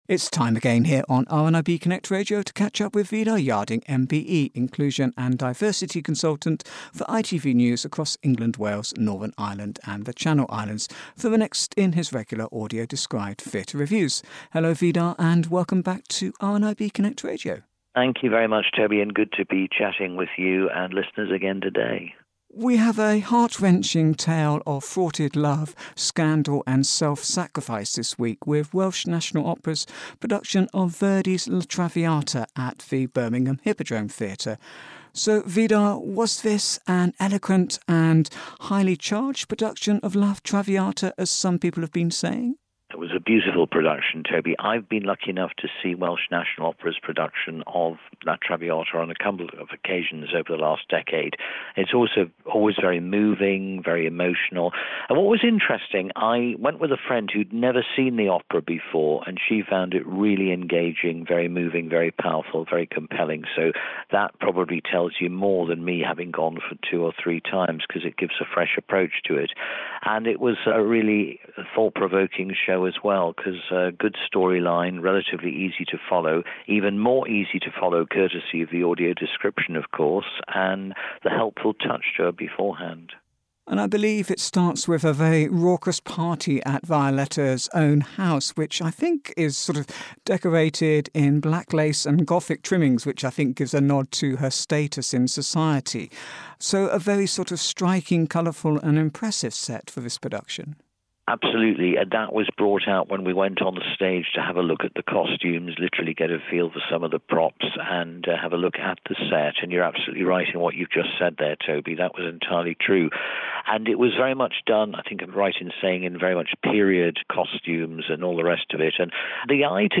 AD Theatre Review